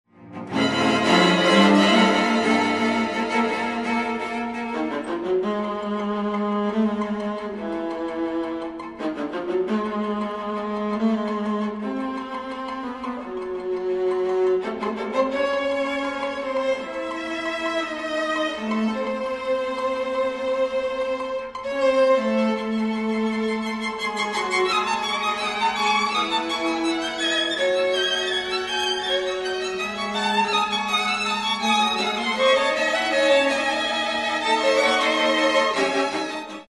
string octet (violin (4), Viola (2), Cello. (2))